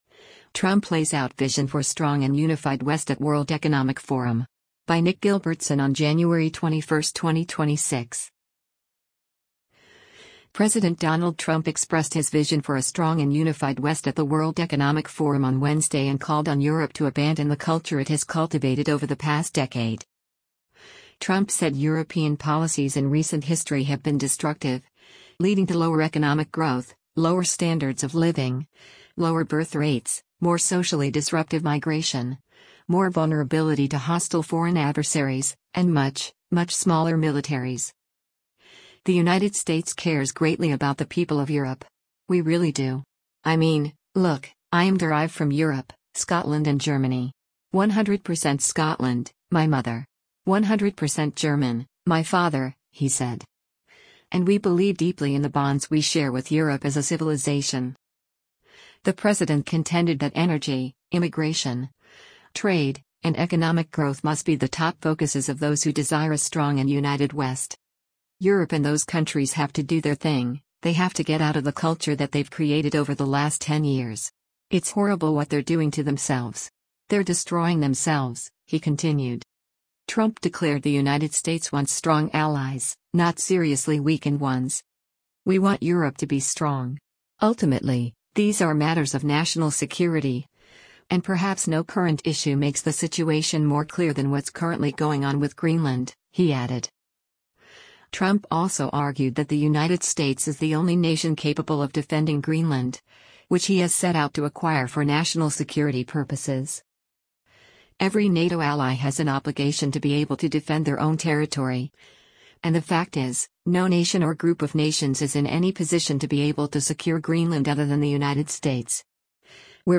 President Donald Trump expressed his vision for “a strong and unified West” at the World Economic Forum on Wednesday and called on Europe to abandon the culture it has cultivated over the past decade.
In his speech, the president told world leaders and elites that he would not seek to take Greenland by “force.”